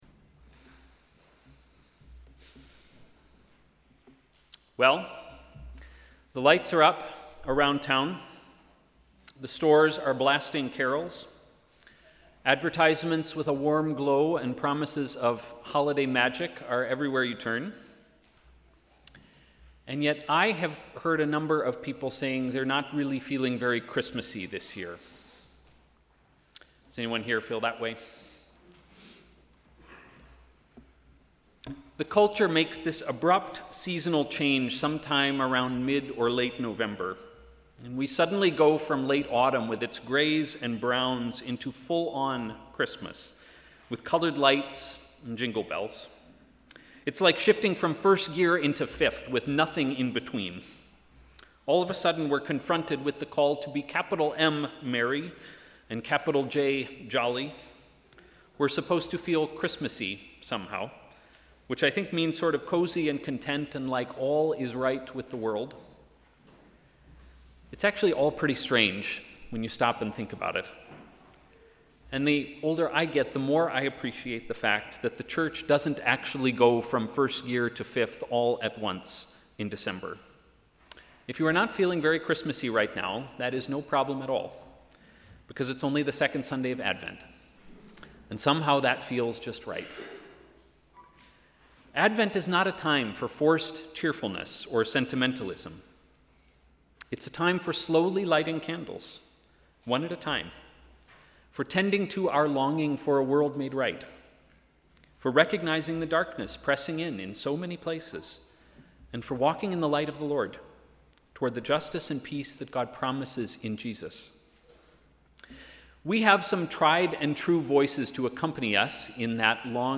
Sermon: ‘From a stump’